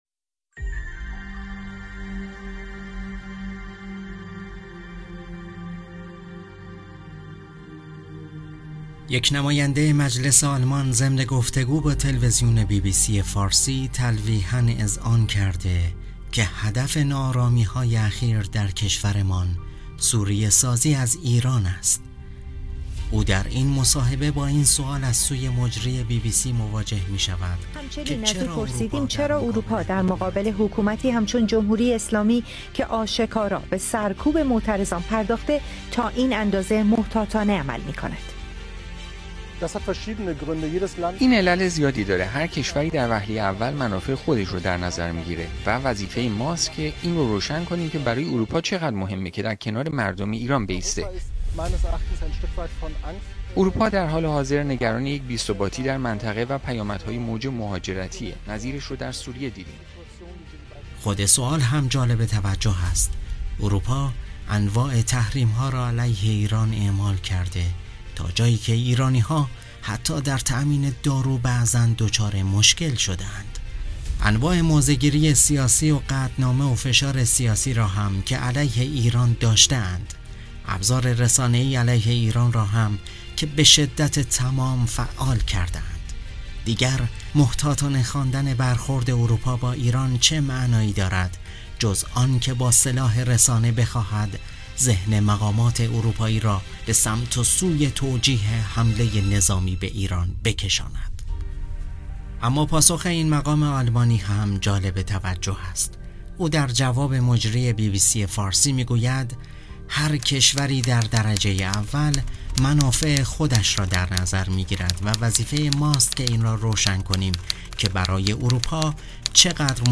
گوینده پادکست